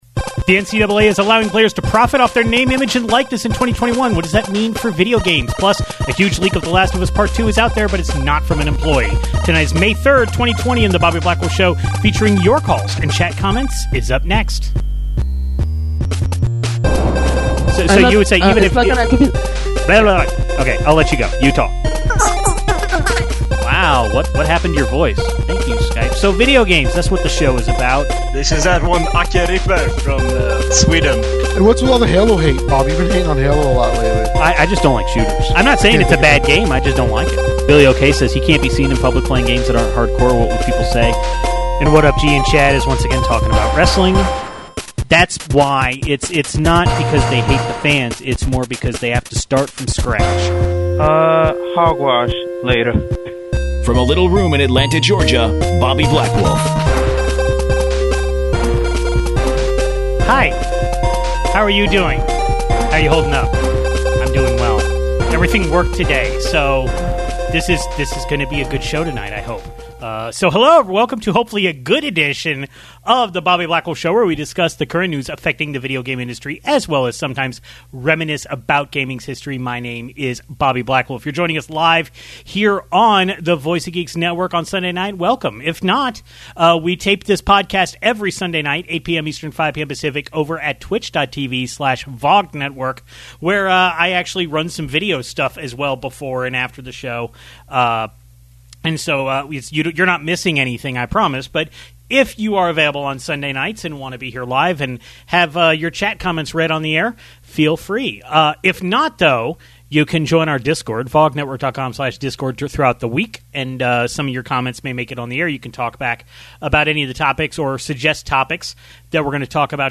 The NCAA will soon allow student athletes to profit off of their Name, Image, and Likeness, but that doesn't mean that the NCAA Football video games are immediately coming back. The Last of Us Part II was leaked heavily but it didn't come from Naughty Dog or Sony. Then we take calls about EVO going online and the beauty of Xbox Game Pass.